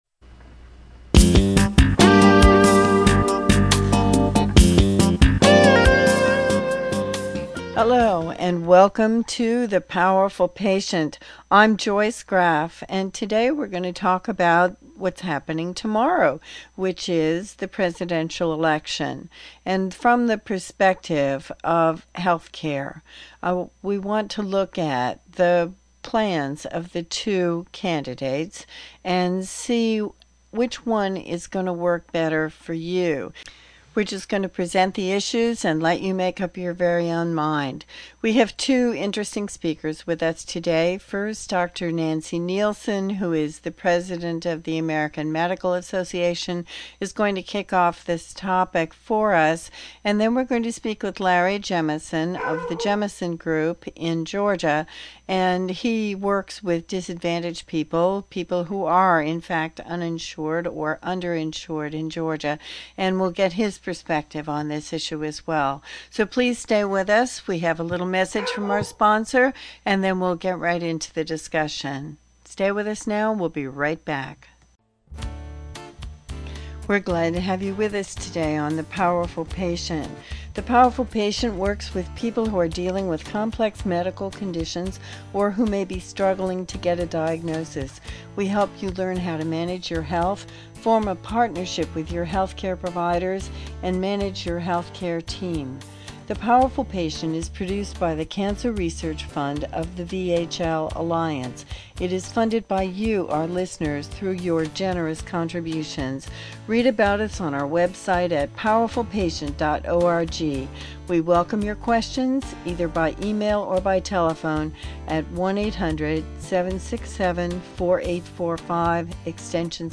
2008 Listen Program guide for this show As the U.S. Presidential election nears, one important debate centers around providing health care for Americans.